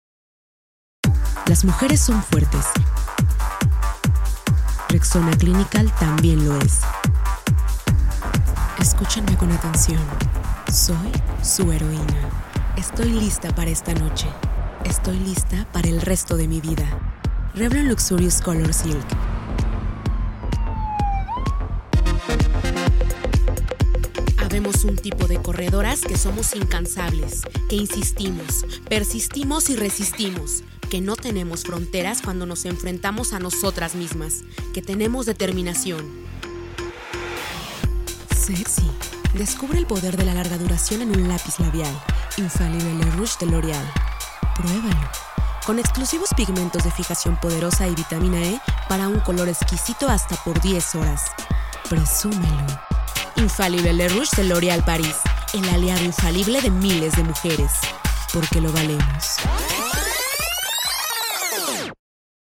Locutora comercial, hispanohablante, voz media, voz juvenil, doblaje de voz
Sprechprobe: Werbung (Muttersprache):